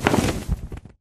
mob / enderdragon / wings5.ogg
wings5.ogg